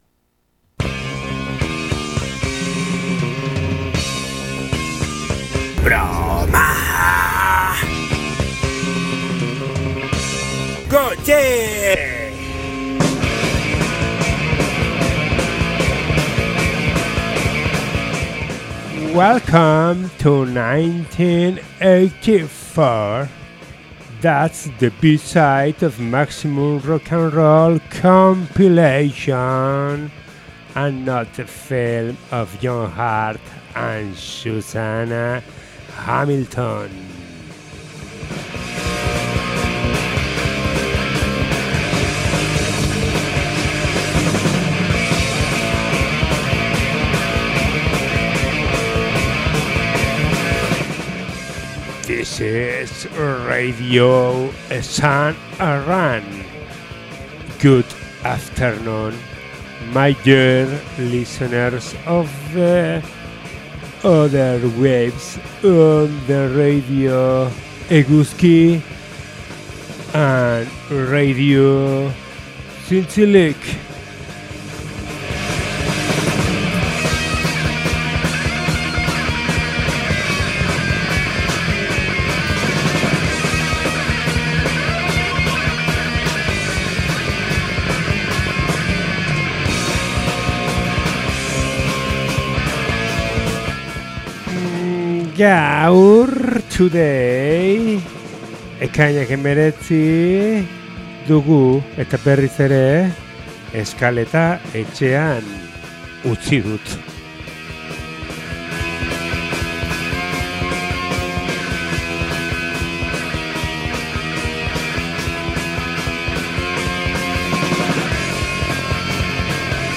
eta hardcore eta punk doinuz osatua… hemen behean duzue gaurko abestien zerrenda.